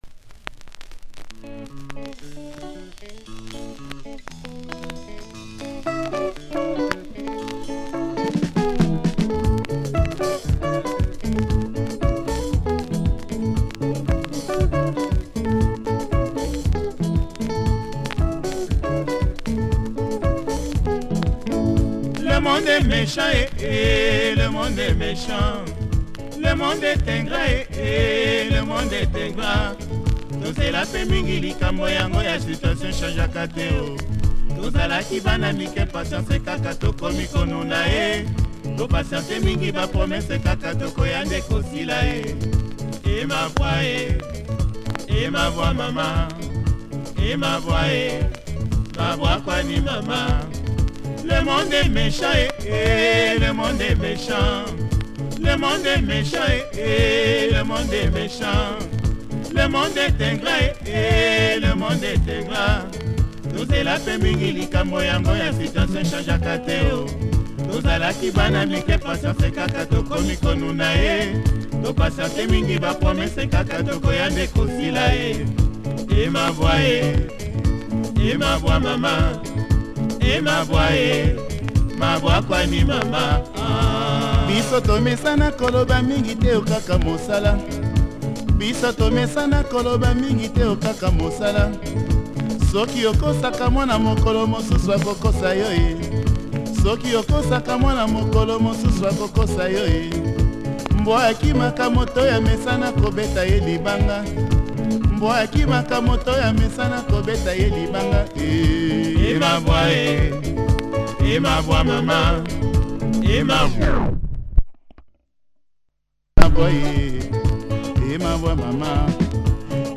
Nice lingala from this Congo outfit. https